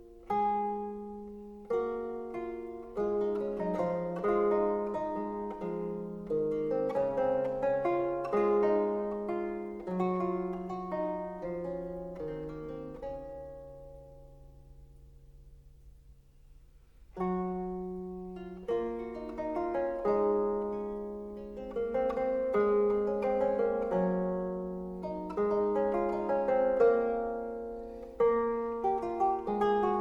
Mon chant vous envoy : virelais, ballades et rondeaux / Guillaume de Machaut, compositeur